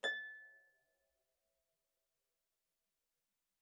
KSHarp_A6_mf.wav